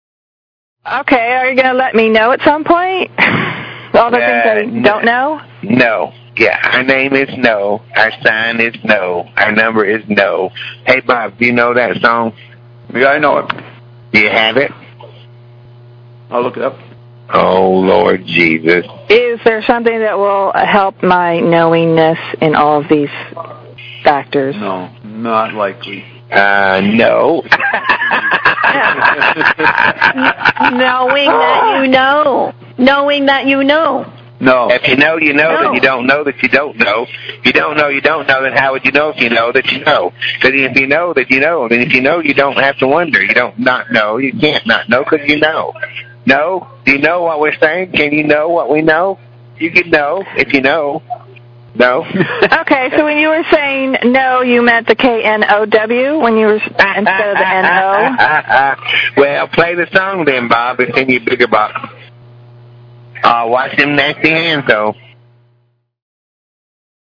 rant on “no/know”